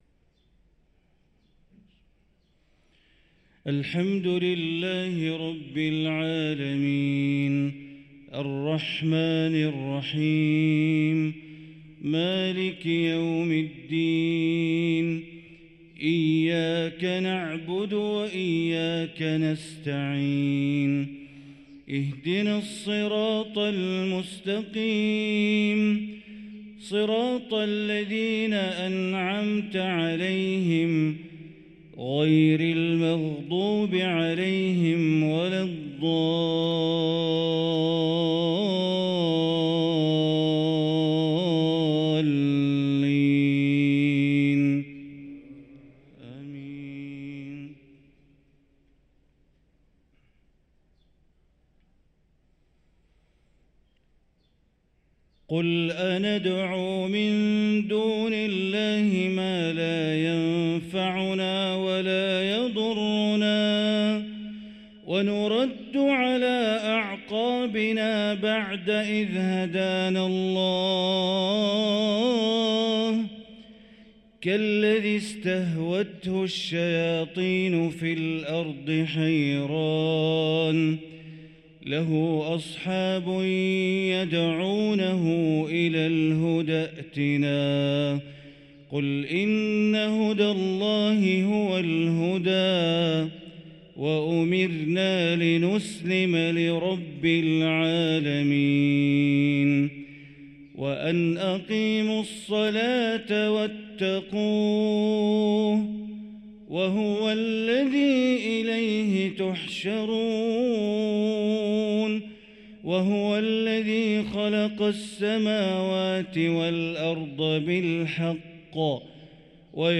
صلاة الفجر للقارئ بندر بليلة 20 ربيع الأول 1445 هـ
تِلَاوَات الْحَرَمَيْن .